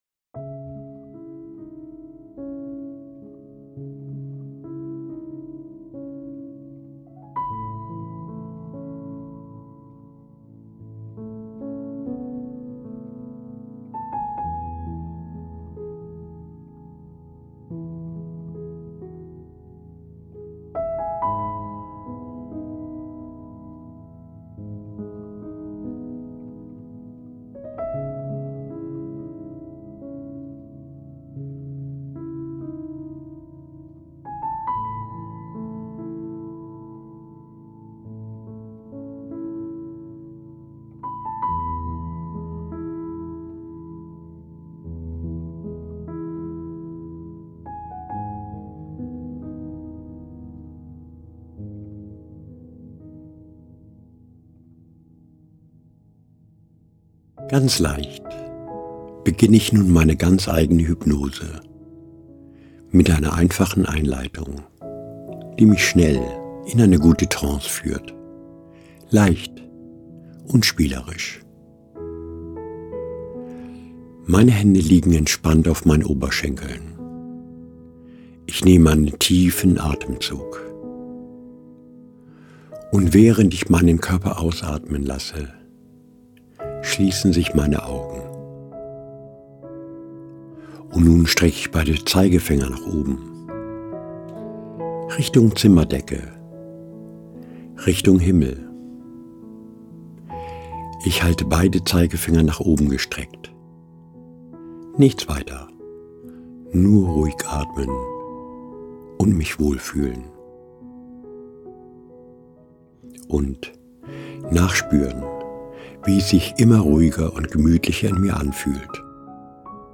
Selbsthypnoseinduktion